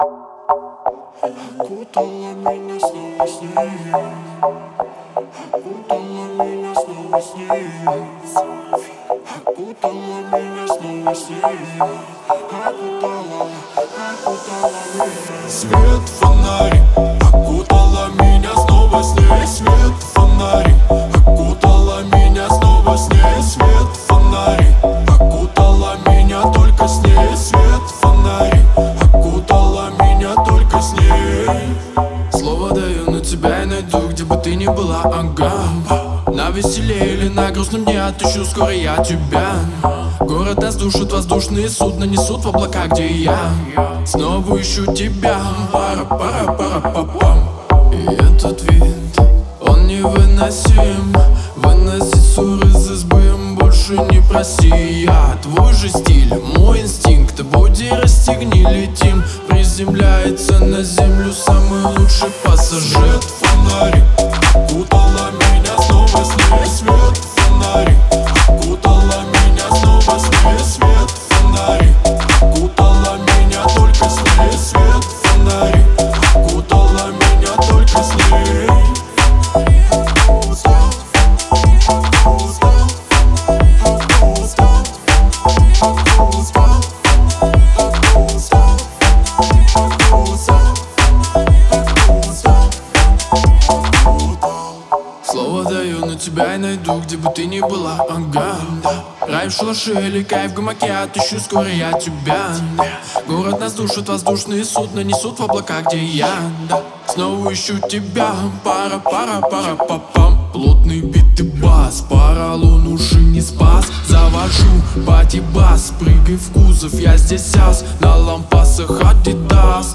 Лаунж